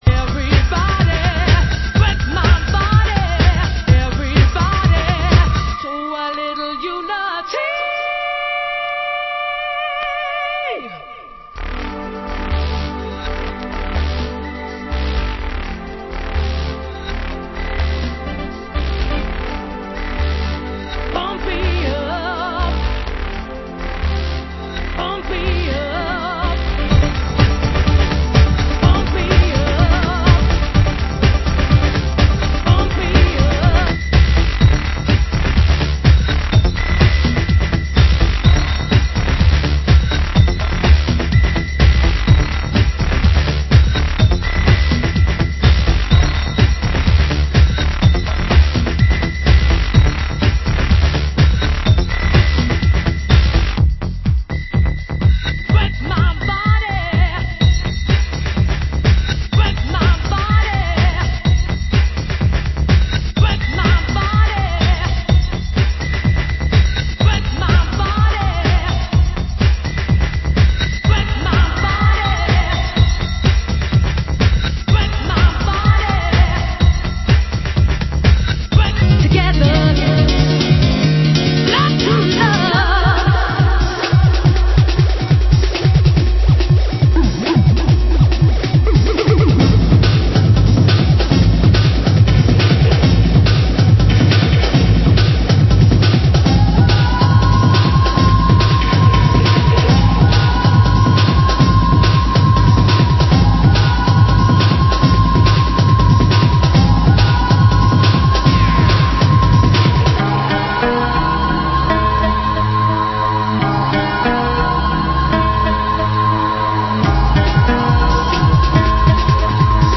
Genre Hardcore